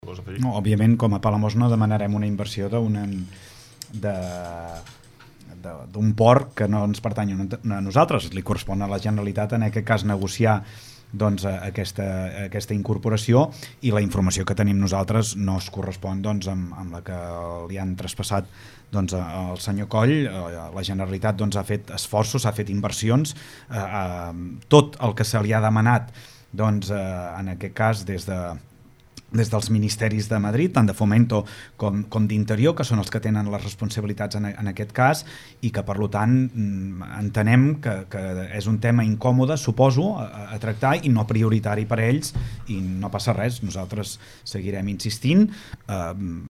Els dos polítics, l’alcalde per Esquerra Republicana (ERC) Lluís Puig, i el socialista Josep Coll, cap de l’oposició, han analitzat l’actualitat municipal als micròfons de Ràdio Capital de l’Empordà.